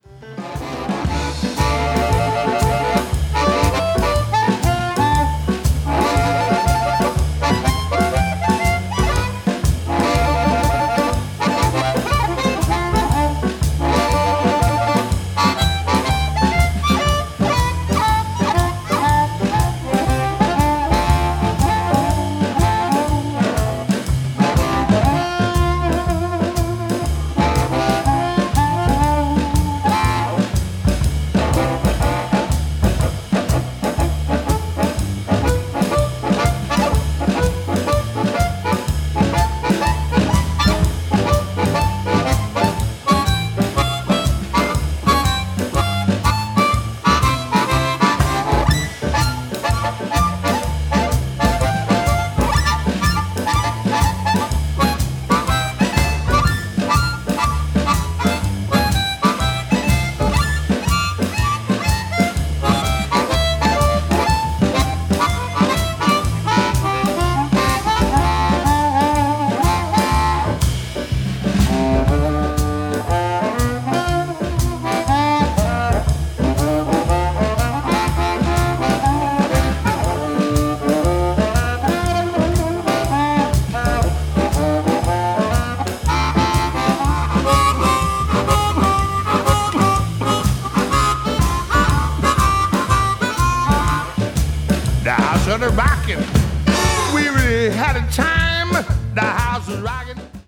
It goes from sparkling clean to brown and creamy distortion.
C Chromonica 64